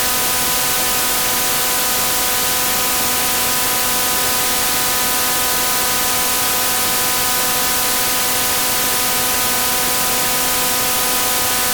Unid_Radar_250Hz-FM.mp3